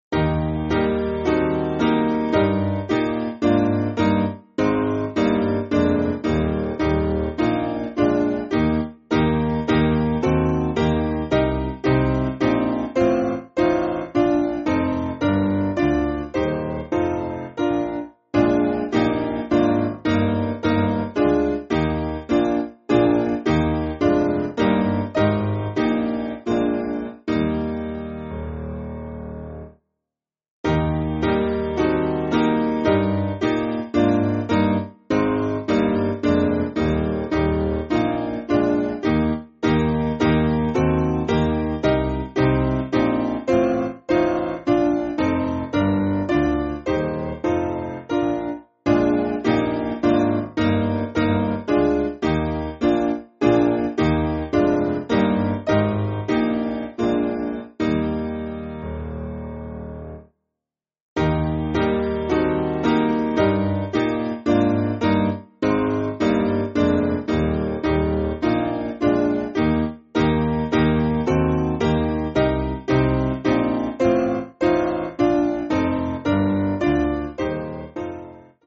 Simple Piano
(CM)   4/Eb